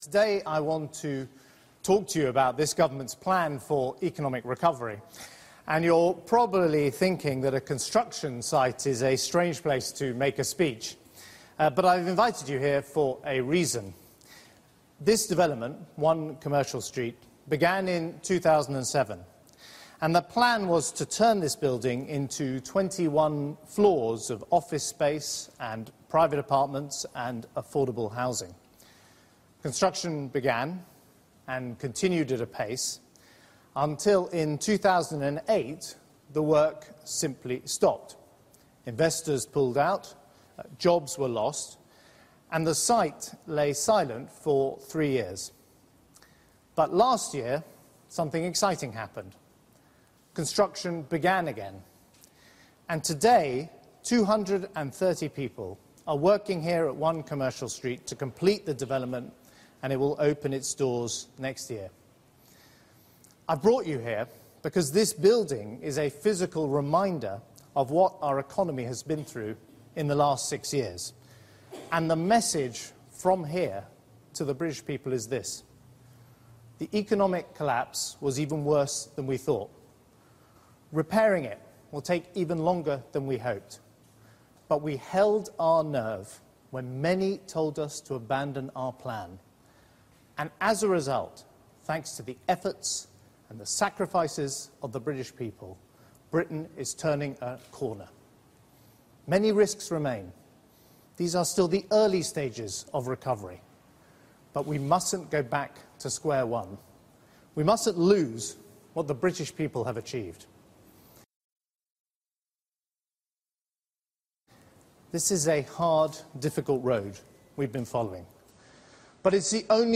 George Osborne speech: British economy is 'turning a corner'